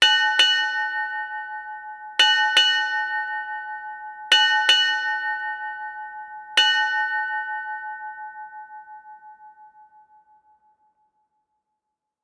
Seven Bells,Ship Time
7-bells bell ding maritime nautical naval sailing seafaring sound effect free sound royalty free Sound Effects